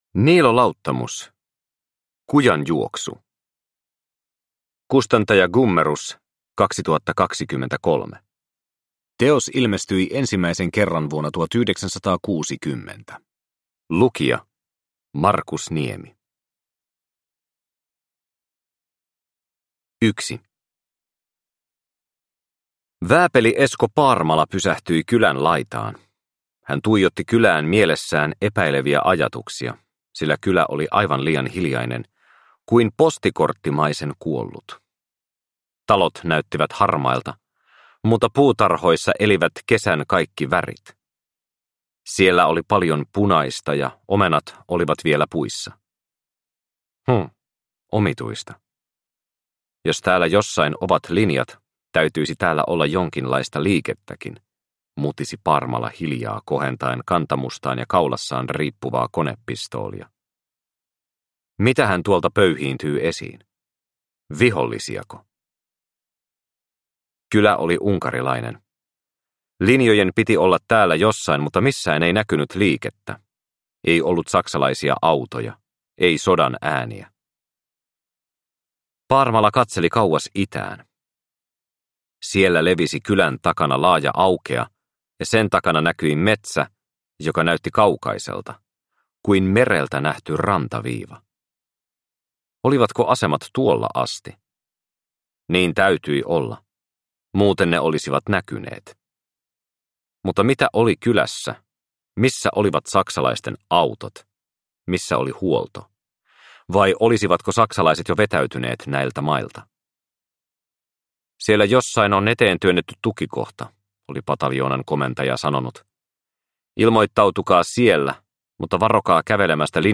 Kujanjuoksu – Ljudbok – Laddas ner